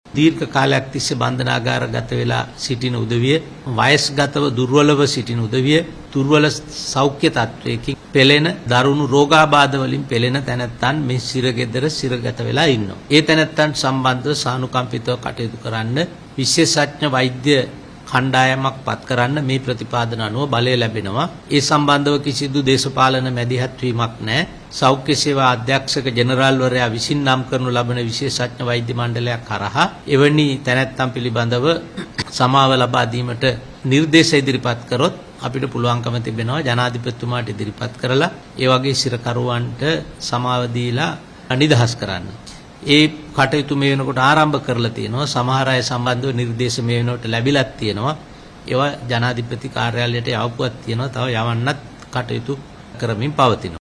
කොළඹ අද පැවති මාධ්‍ය හමුවකට එක්වෙමින් අධිකරණ අමාත්‍යවරයා මේ බව ප්‍රකාශ කළා.